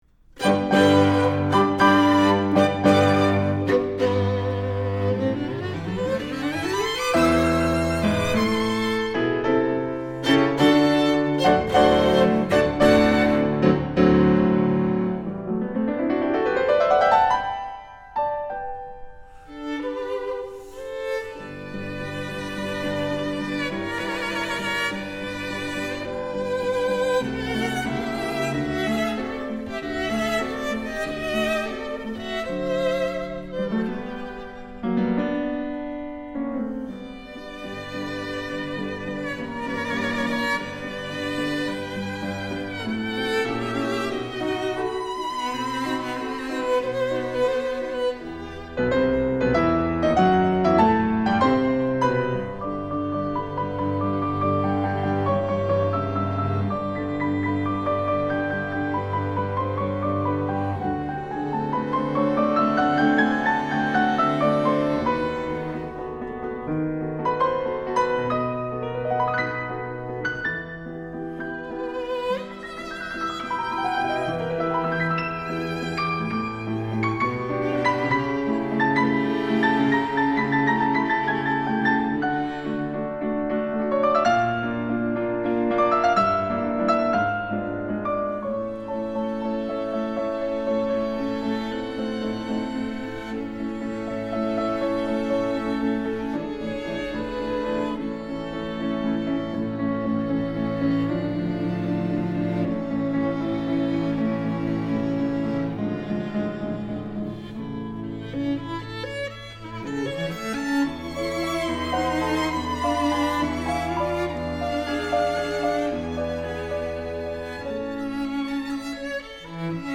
Soundbite 1st Movt
for Violin, Cello and Piano